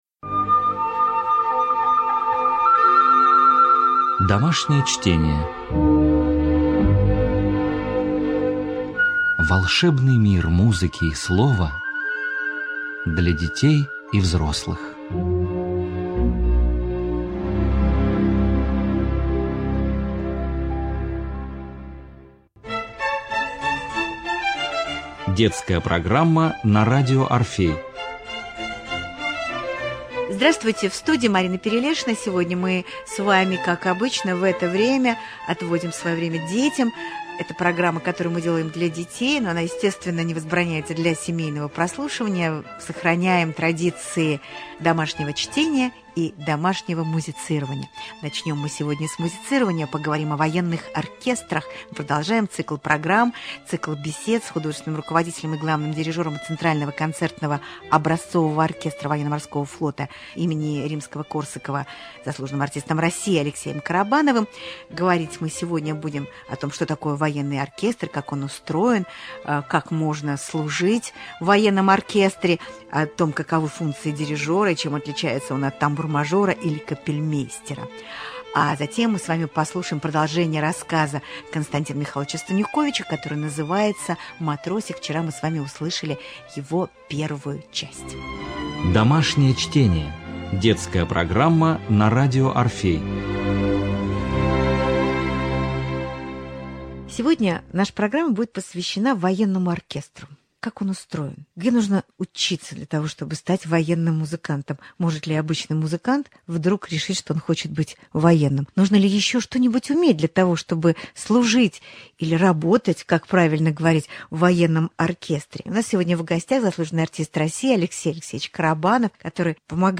Цикл бесед о патриотической и военной музыке